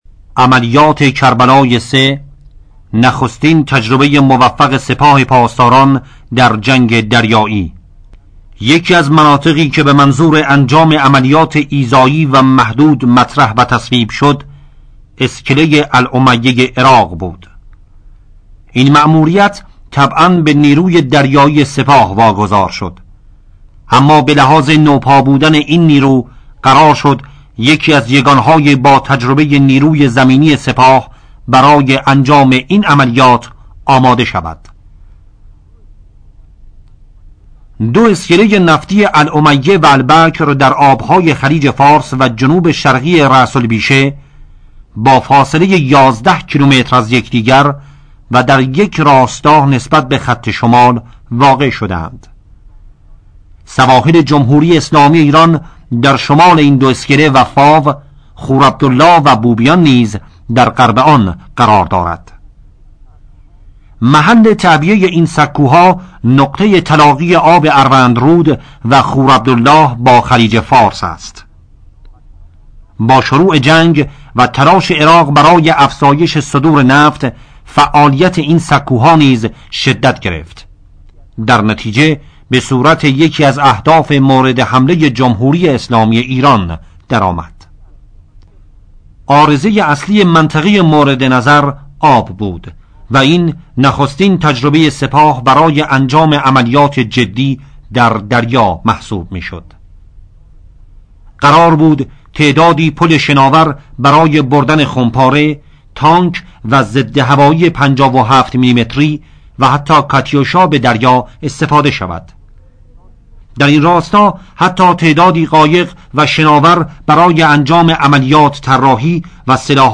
صوت شرح عملیات